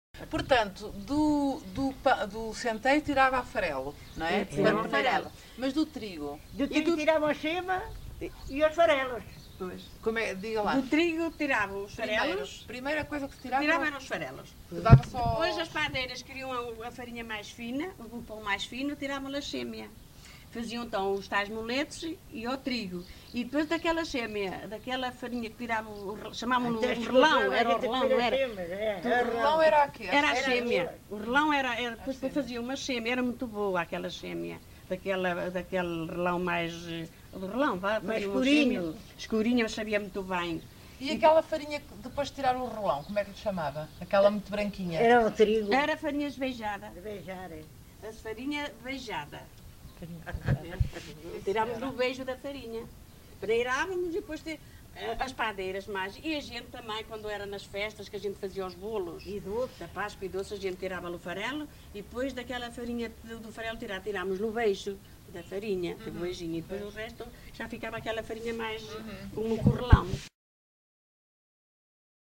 LocalidadeLarinho (Torre de Moncorvo, Bragança)